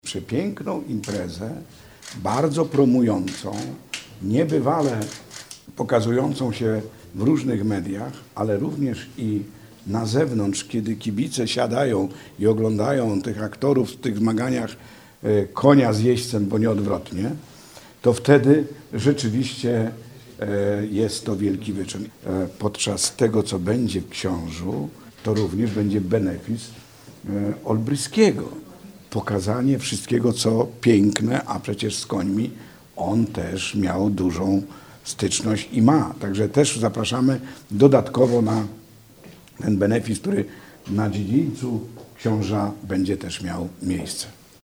Tegoroczna edycja Art Cup odbędzie się po raz pierwszy w Stadzie Ogierów w Książu. Mówi Jerzy Pokój, Przewodniczący Sejmiku Województwa Dolnośląskiego.